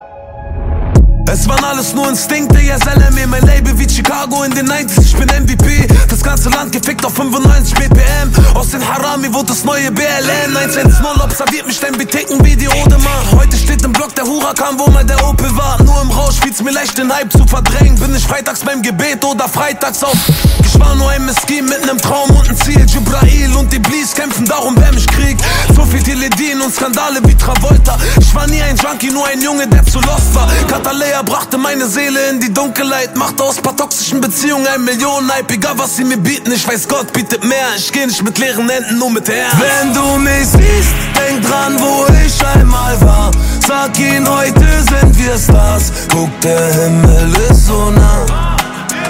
Kategorien Rap